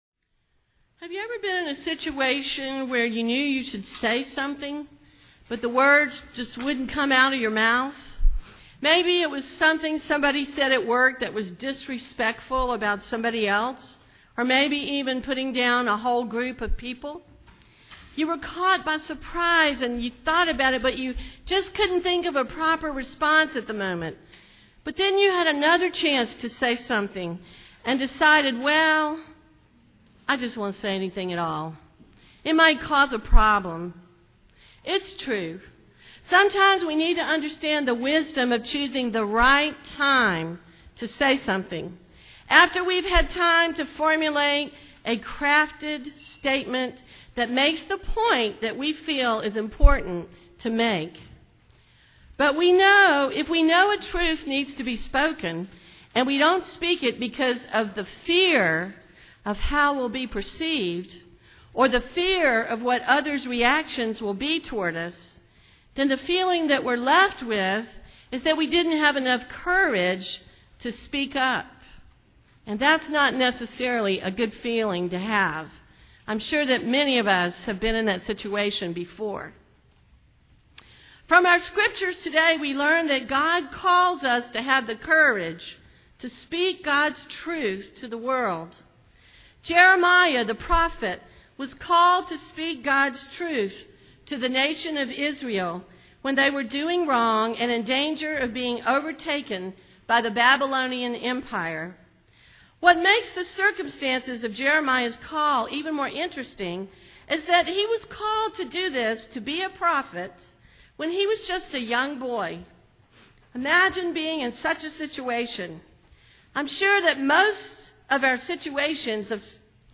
Sermon from February 3